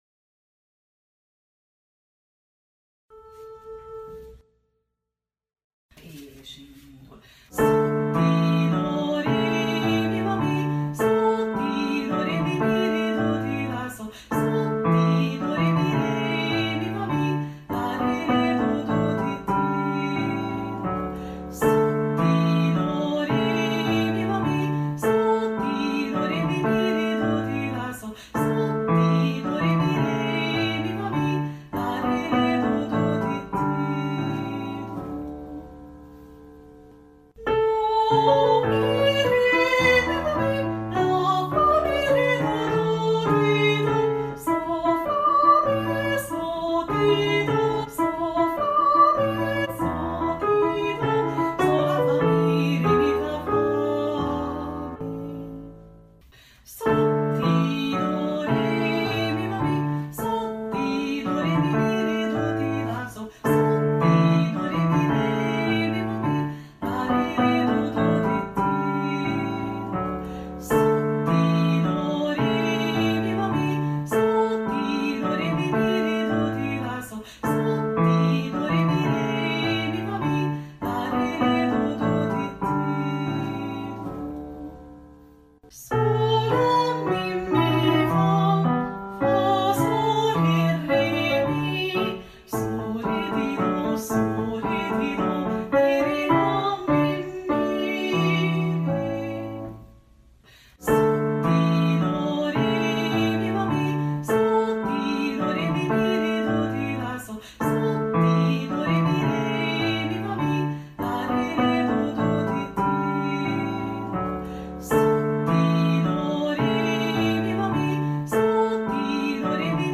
A változatosság kedvéért a felvételek otthon készültek. Vigyázat, nyomokban speciális effekteket (zajokat) tartalmazhatnak.